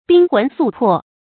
冰魂素魄 bīng hún sù pò
冰魂素魄发音